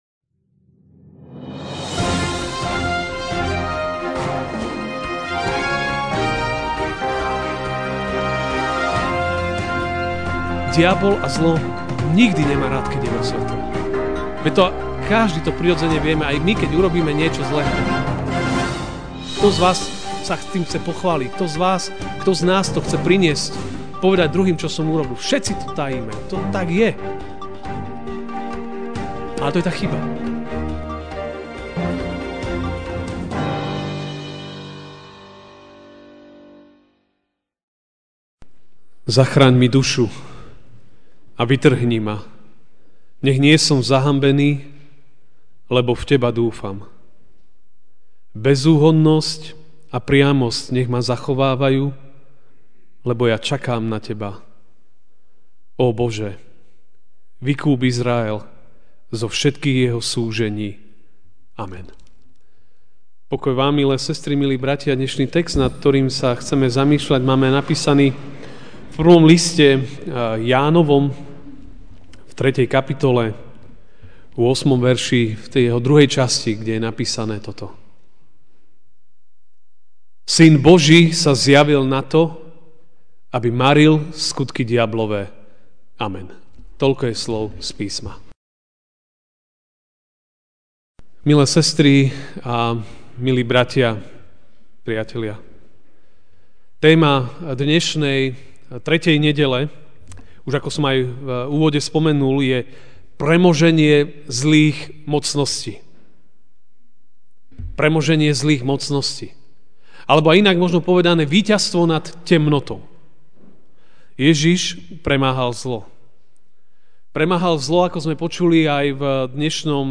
mar 04, 2018 Víťazstvo nad temnotou MP3 SUBSCRIBE on iTunes(Podcast) Notes Sermons in this Series Ranná kázeň: Víťazstvo nad temnotou (1.